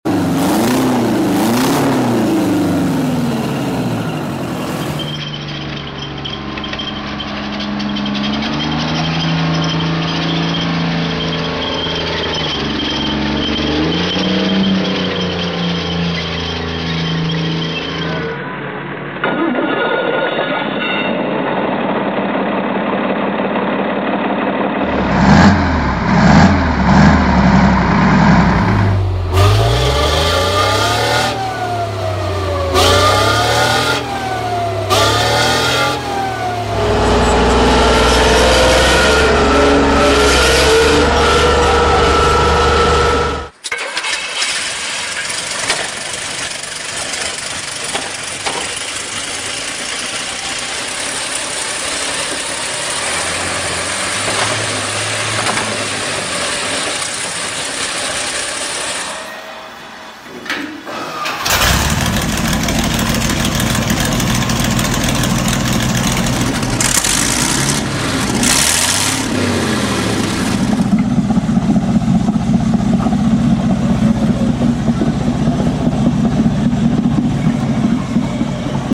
Big Tank Engines Starting Up Sound Effects Free Download